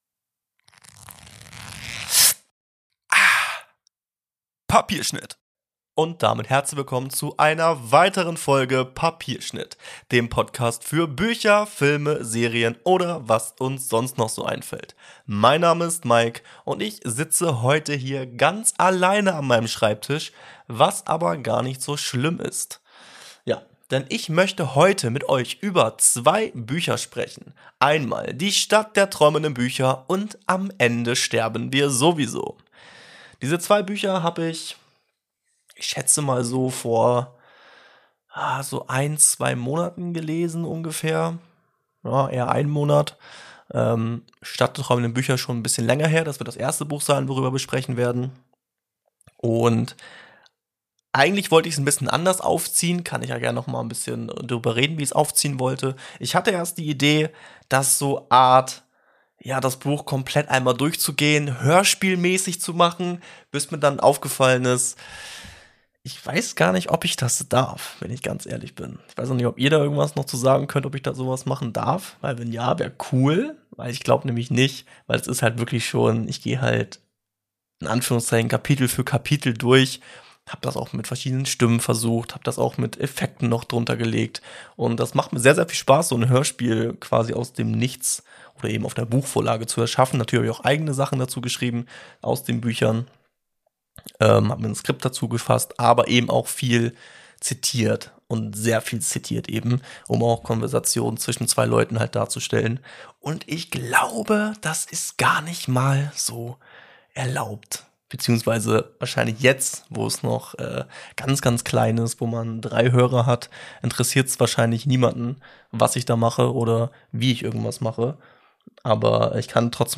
Heute habe ich mich mal ganz alleine hinter mein Mikrofon geklemmt und berichte von Zwei, ja richtig gelesen, zwei Büchern die ich vor kurzem gelesen habe.